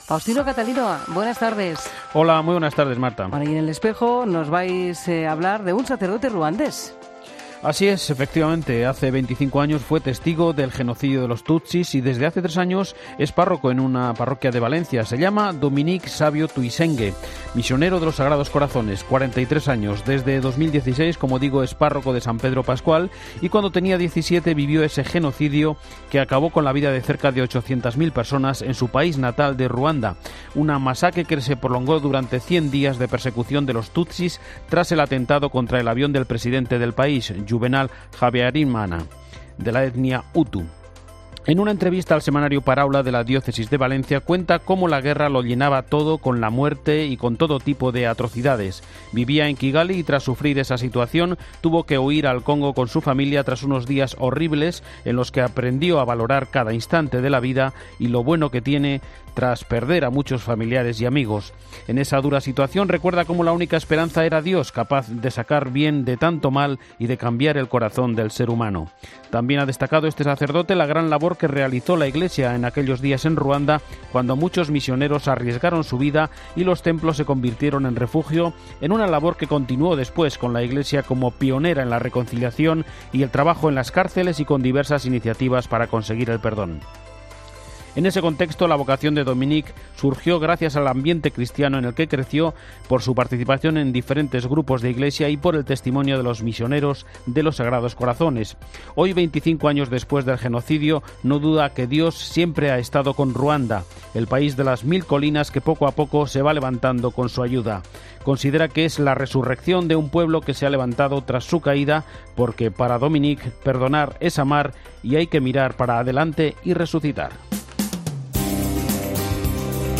En Espejo 23 abril 2019: Entrevista sobre Las Edades del Hombre